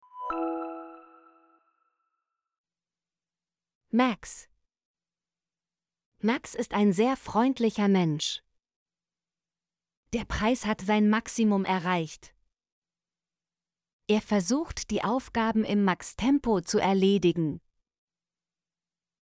max - Example Sentences & Pronunciation, German Frequency List